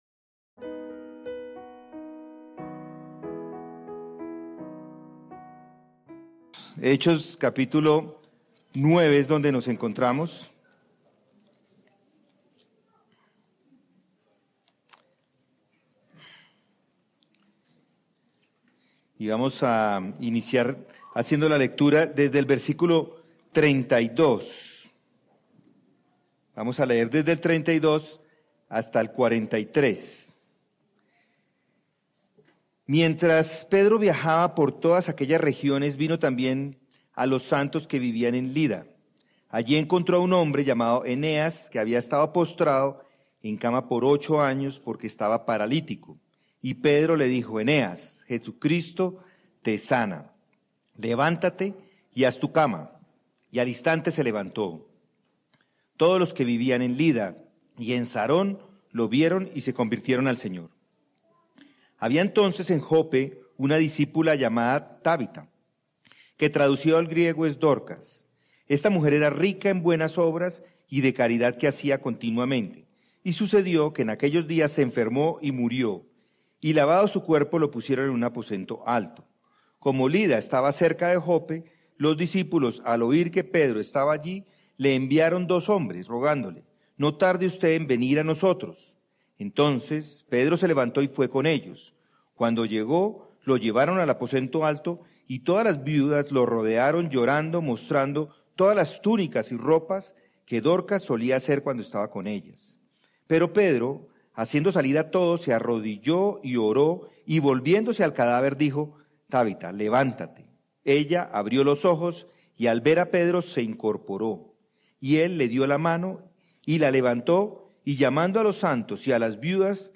Sermones | Comunidad de Gracia